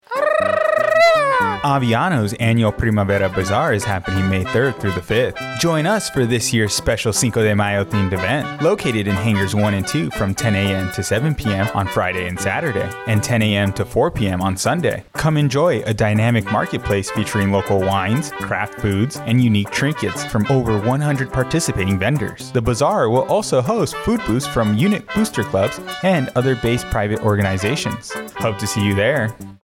A radio spot for Aviano Air Base’s annual Primavera Bazaar; featuring local wines, craft foods, and trinkets from over 100 European participating vendors scheduled May 3-5, 2024.